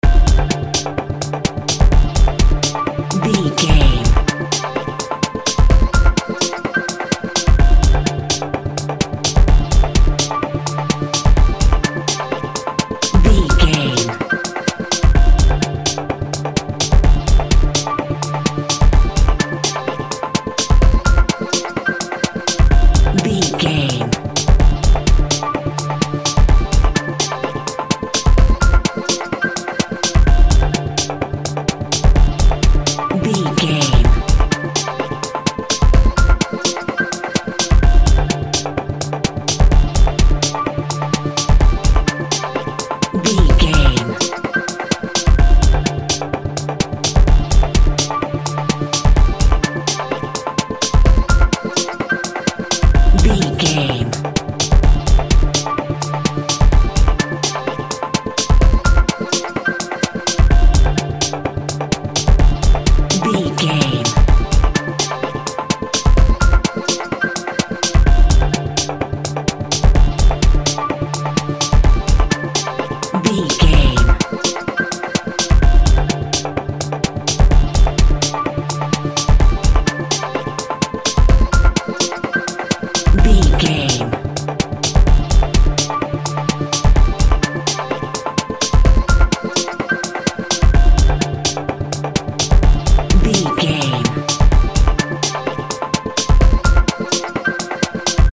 Epic / Action
Fast paced
Aeolian/Minor
ethereal
dreamy
frantic
futuristic
driving
energetic
piano
synthesiser
Drum and bass
break beat
electronic
sub bass
synth lead